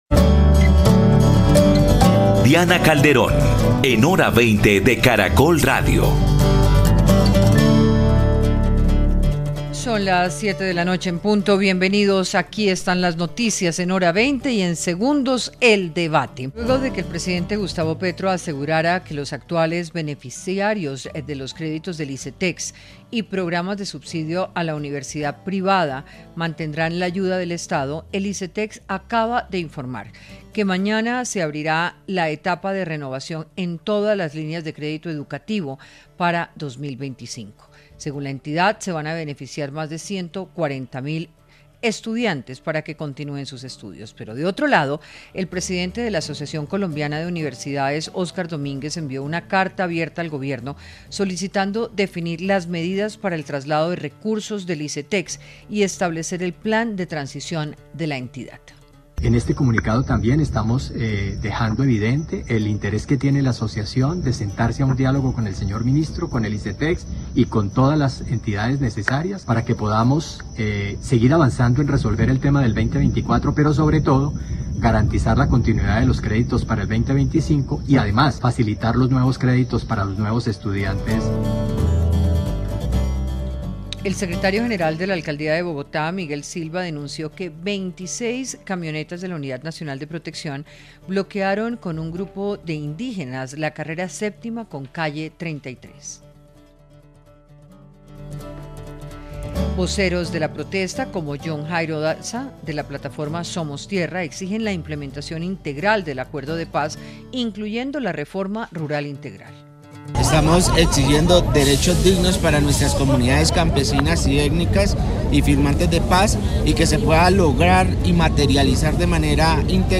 Panelistas debatieron sobre los fenómenos migratorios, la salida de colombianos hacia el exterior y las necesidades diplomáticas para evitar que se imponga el requisito de visa.